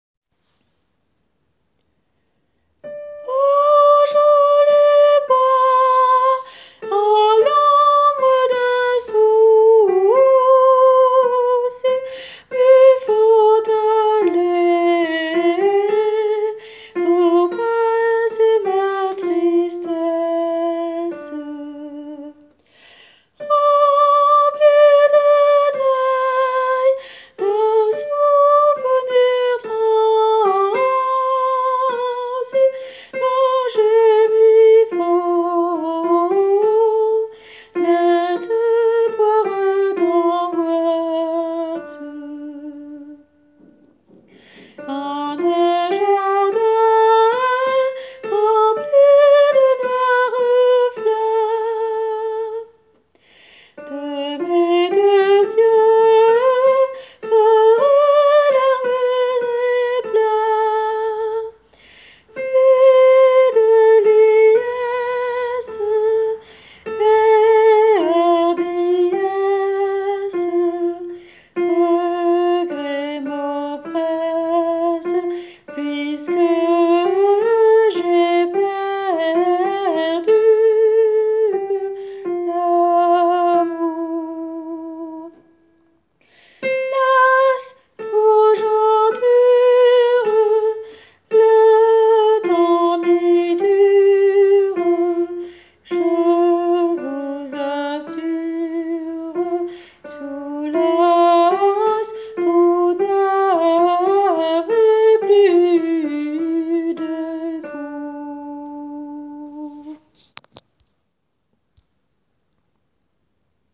[private role=”subscriber”][/private]Ténor :
joli-bois-tenor.wav